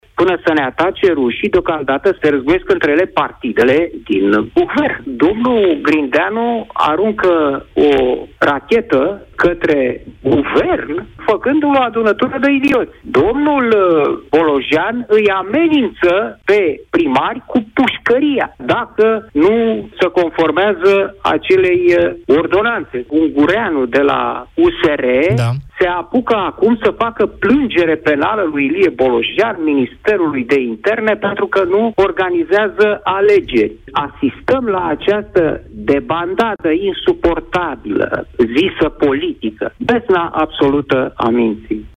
Organizarea deficitară vine pe fondul haosului din Guvern, mai spune gazetarul Cristian Tudor Popescu.